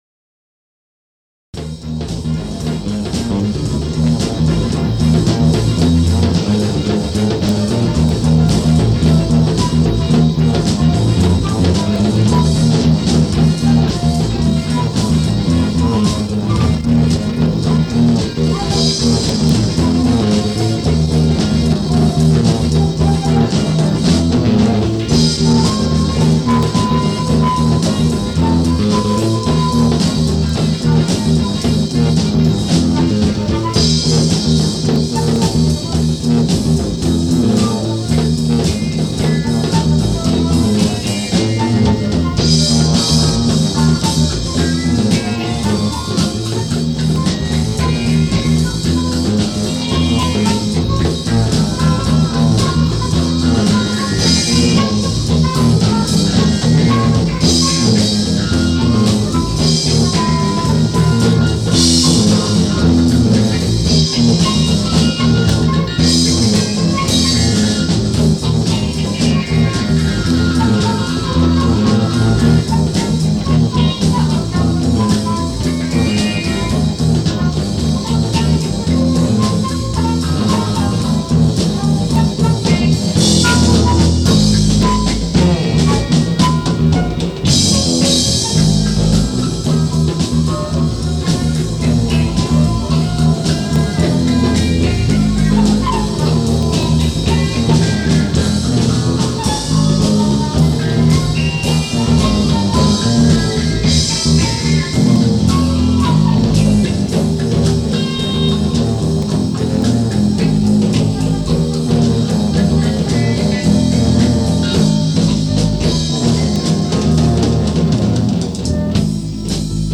Gespielt und aufgenommen mit einem Equipment,